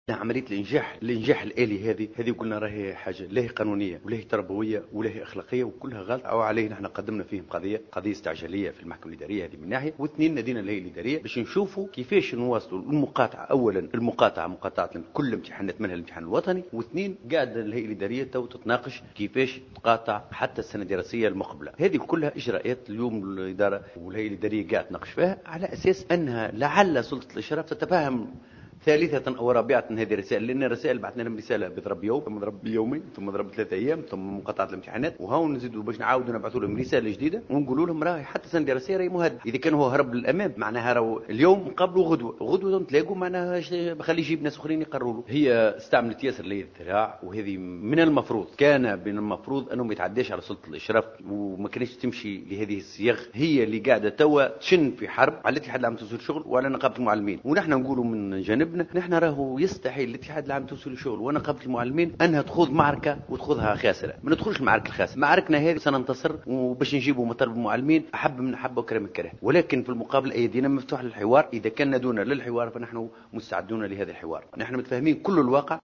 تصريح لمراسلة جوهرة اف ام